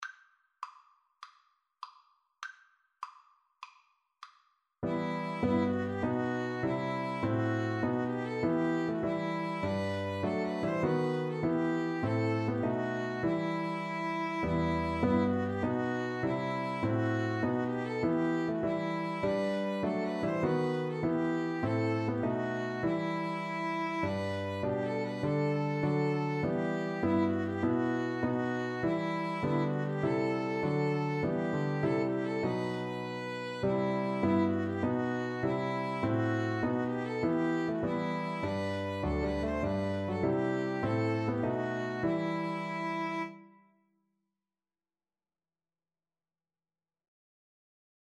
4/4 (View more 4/4 Music)
Violin-Cello Duet  (View more Easy Violin-Cello Duet Music)
Classical (View more Classical Violin-Cello Duet Music)